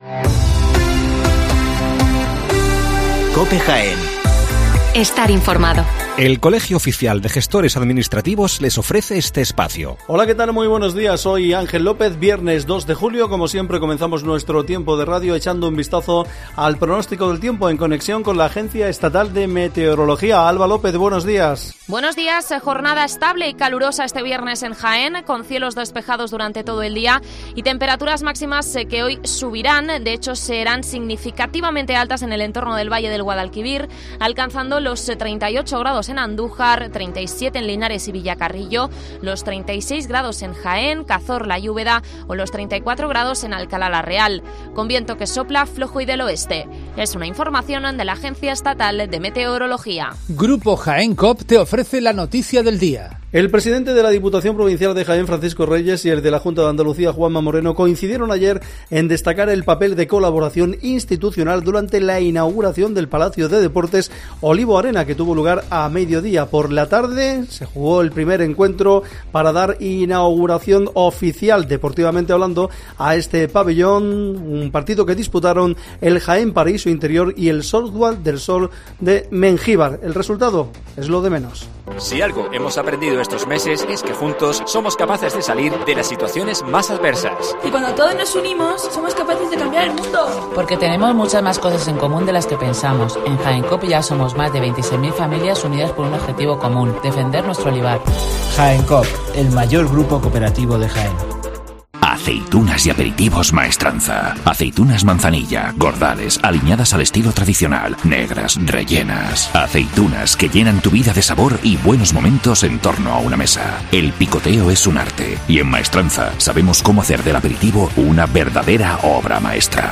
NOTICIAS DE JAÉN